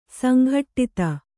♪ sanghaṭṭita